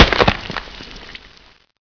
bodysplat.ogg